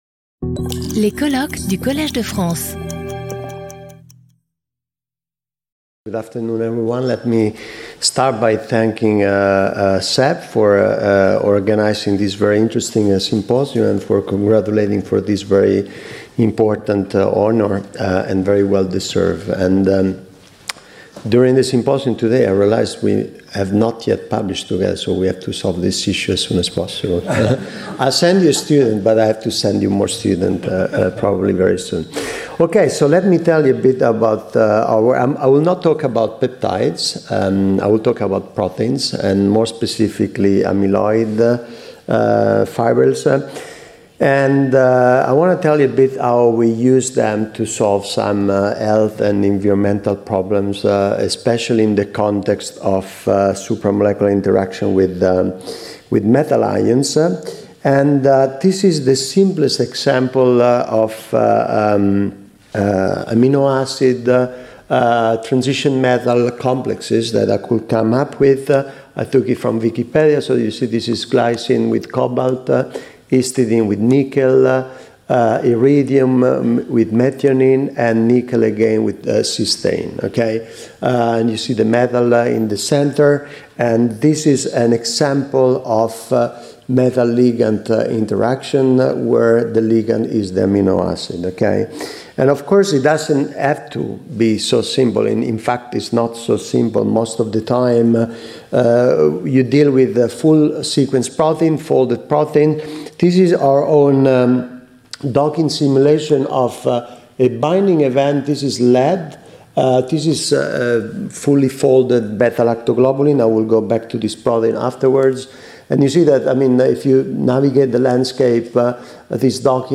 In this talk I will provide several examples of food amyloid fibrils interacting with metal ions and nanoparticles for both health and environmental remediation, some of which have made it into real technologies.